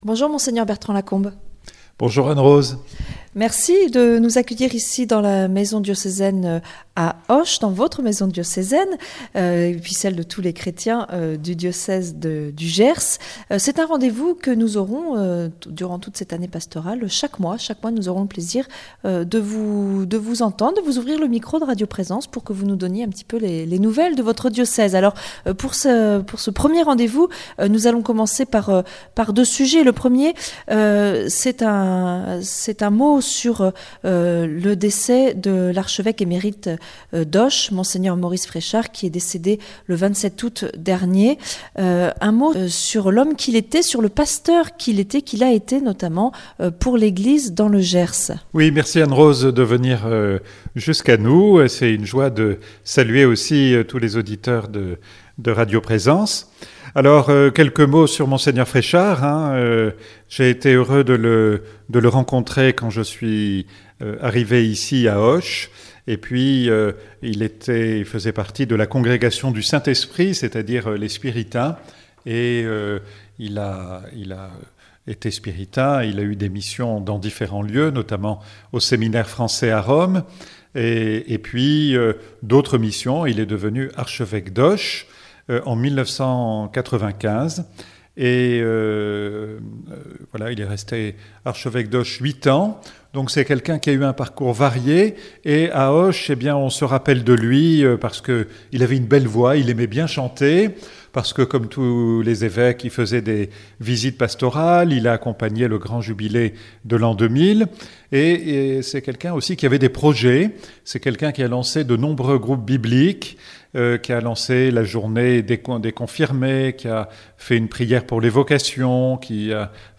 Interview de Mgr. Bertrand Lacombe Archevêque du diocèse d'Auch